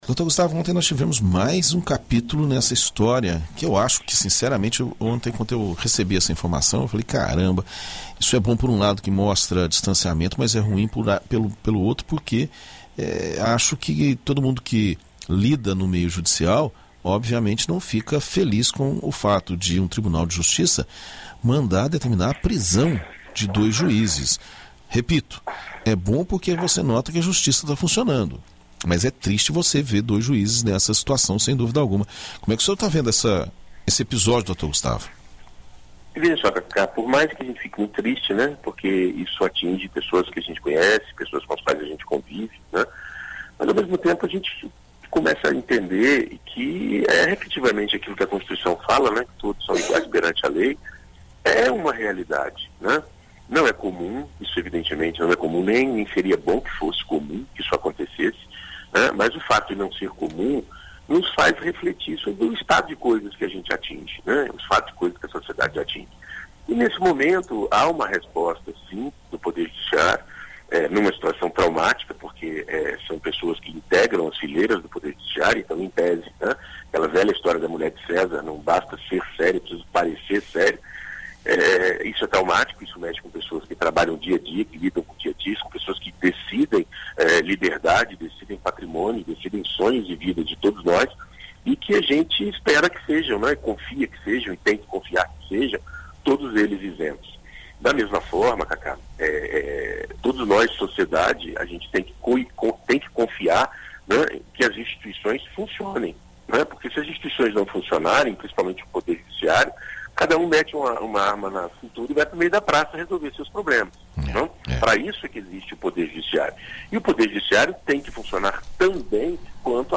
Na coluna Direito para Todos desta sexta-feira (30), na BandNews FM Espírito Santo